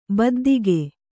♪ baddige